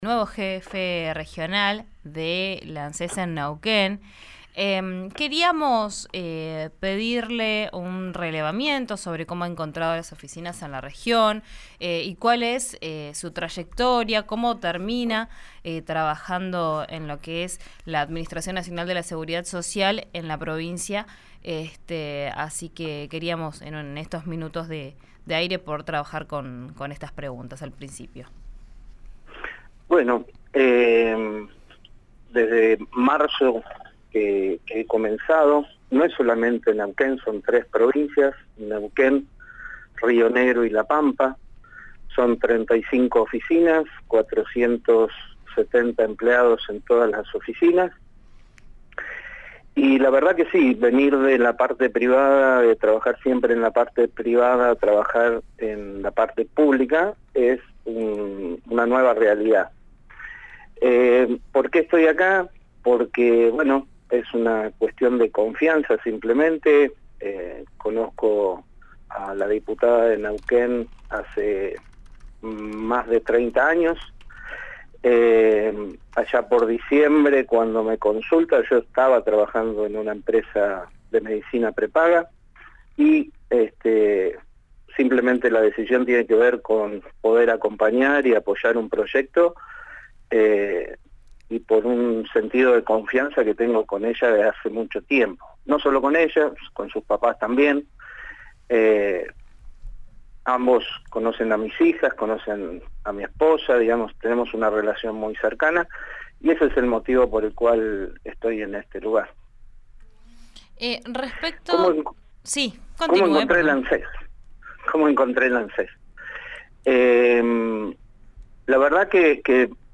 Escuchá a Andrés Chialva en Vos Al Aire, por RÍO NEGRO RADIO